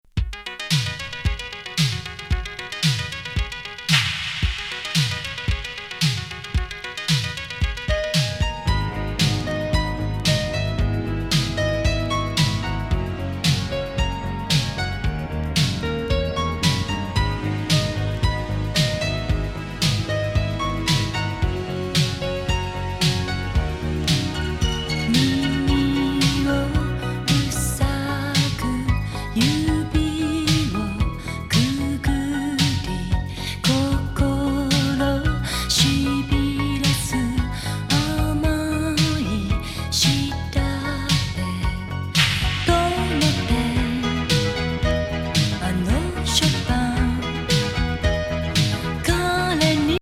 Euro Disco